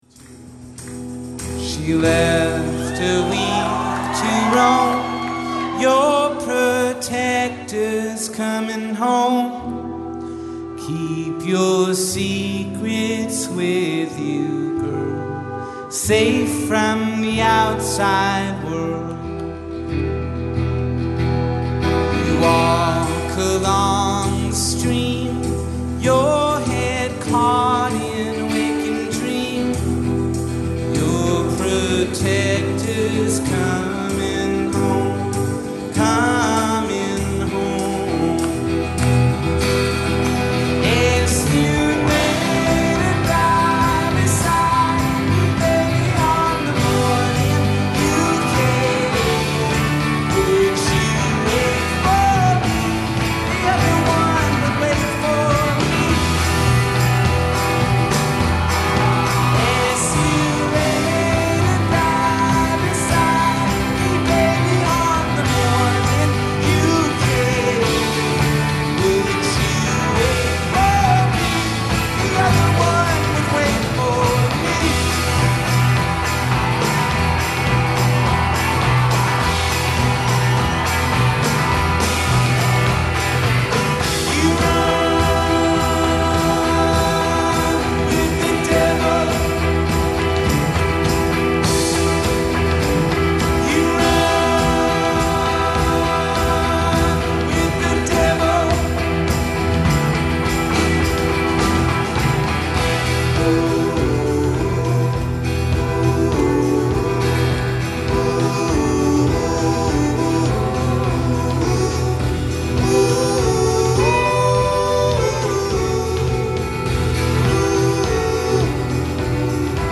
folk-rock americano